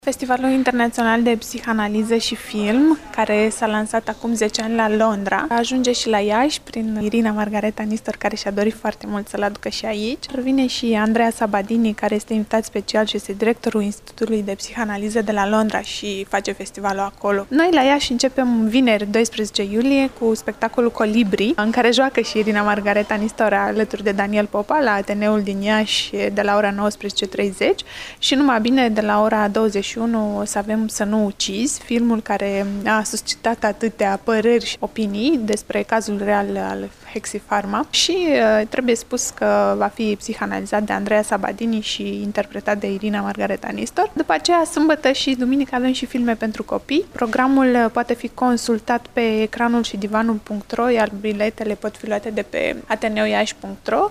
a vorbit reporterului nostru